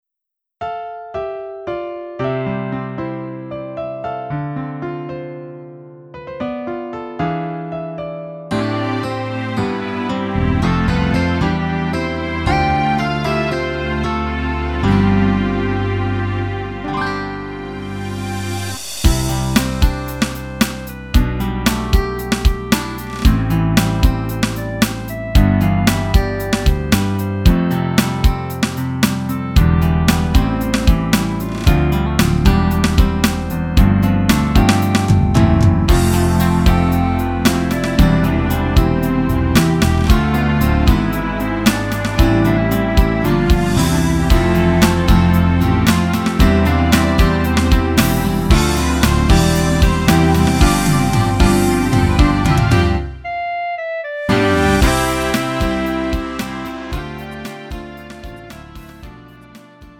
음정 -1키 4:05
장르 구분 Lite MR